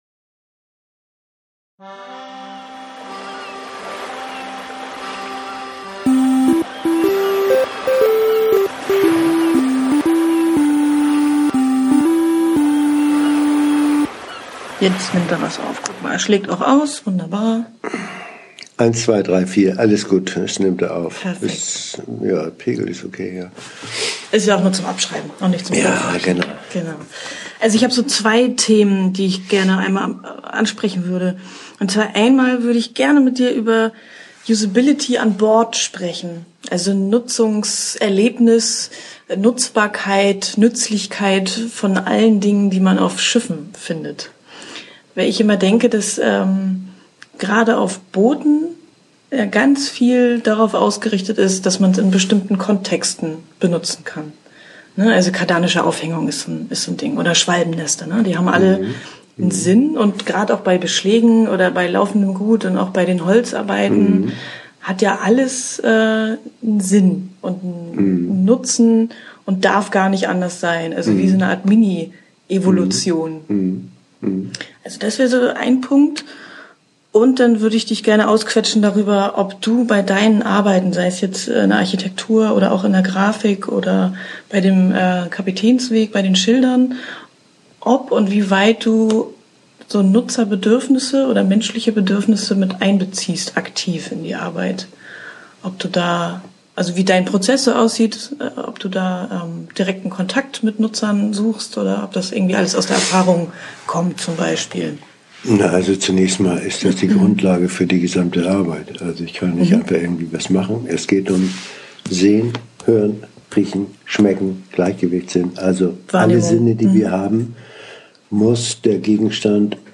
Der ungeschminkte Interview-Mitschnitt.